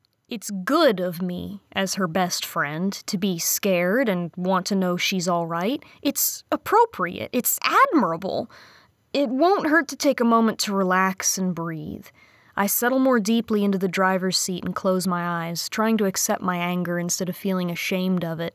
It had an Essing problem which I cured with simple equalization.
Attach 1: Overly Crisp Clip.